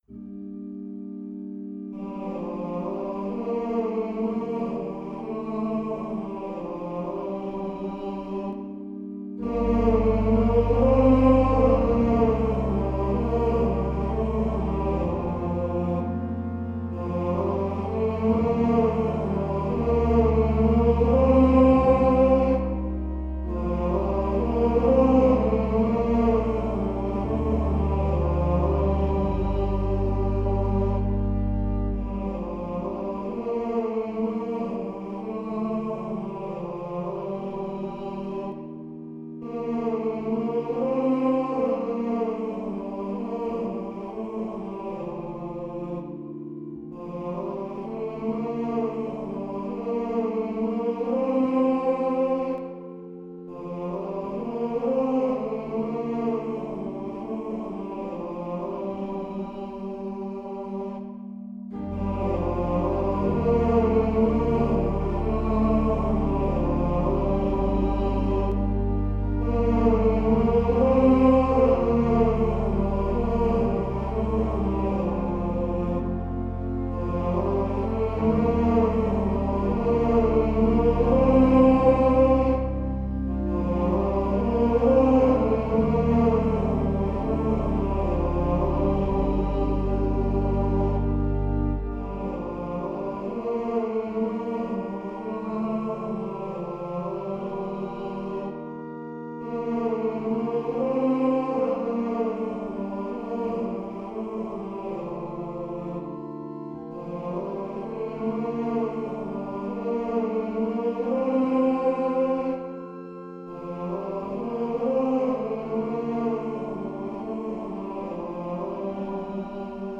Office hymn harmonisations
Since it has been the custom to sing Gregorian office hymns in the Choral Evensongs at the Dominicanenklooster (Dominican Convent) in Zwolle, I have composed an organ accompaniment each time.